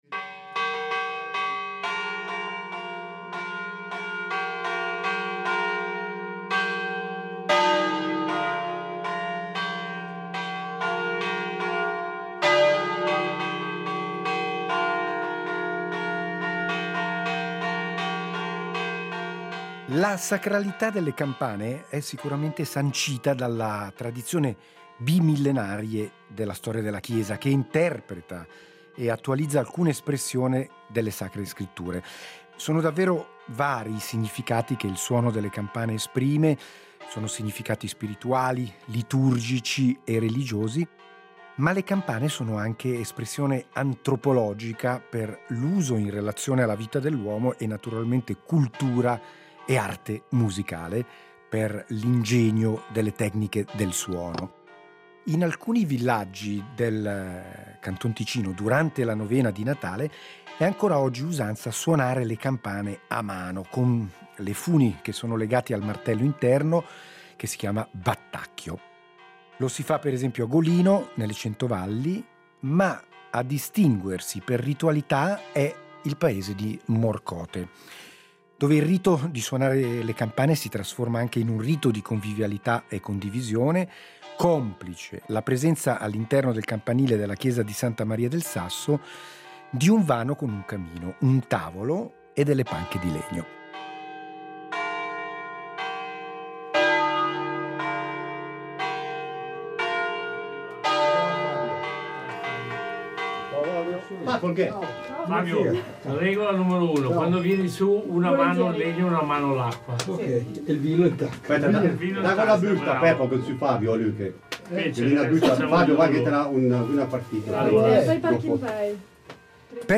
In alcuni villaggi della Svizzera italiana, durante la Novena di Natale, è consuetudine suonare le campane a mano.
Chiunque, a turno, ha l’opportunità di salire sul campanile e intonare melodie semplici e festose.